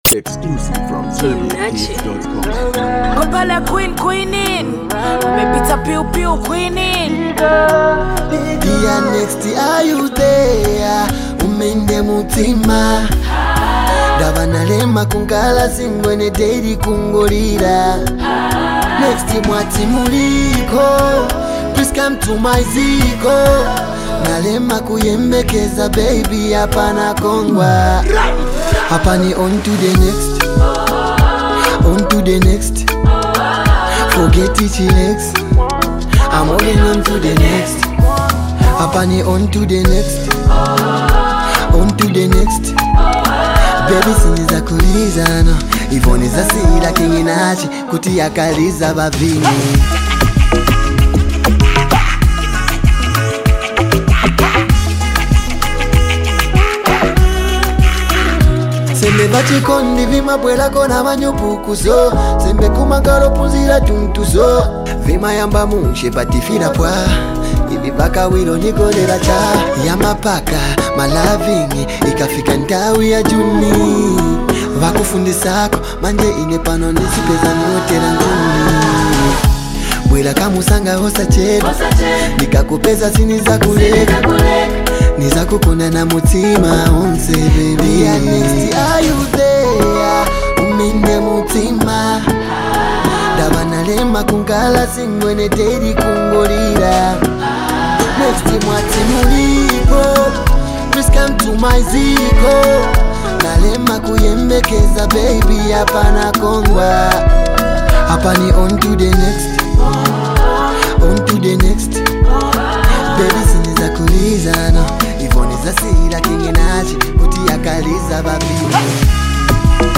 smooth vocals
powerful rap delivery and unique voice
love song